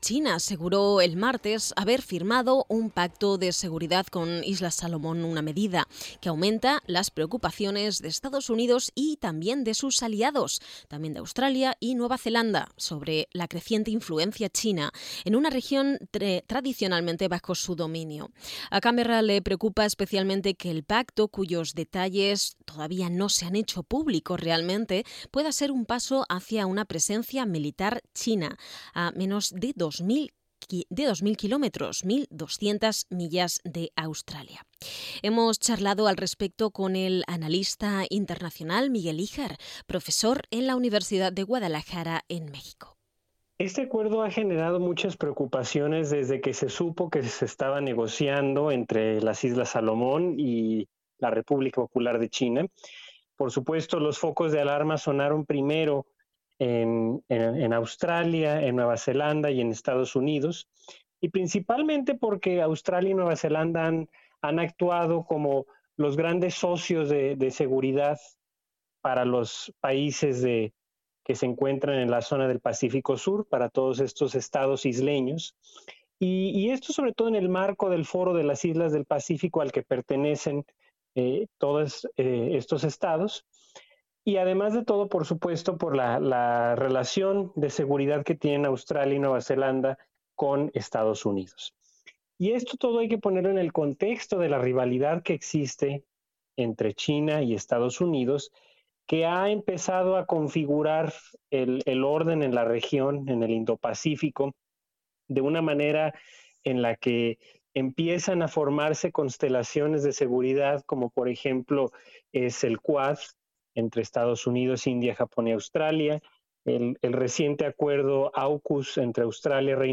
Noticias SBS Spanish | 20 abril 2022